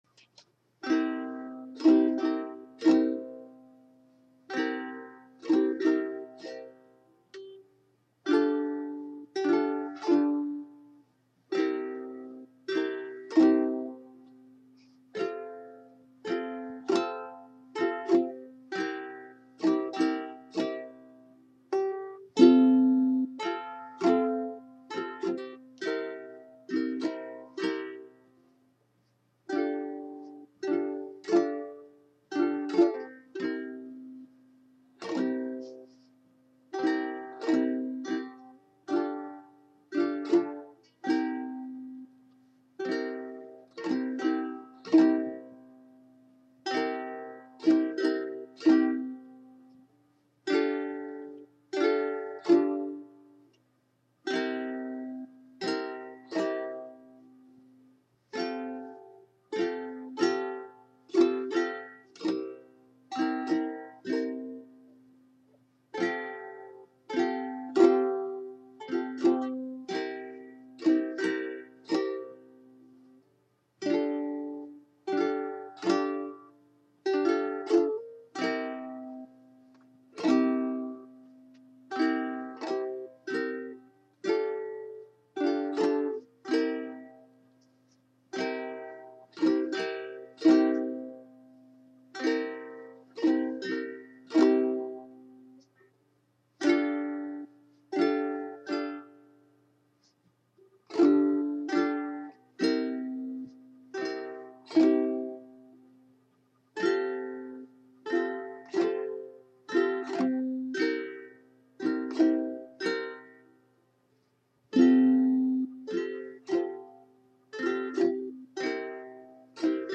Silent Night – on the ukulele, played by me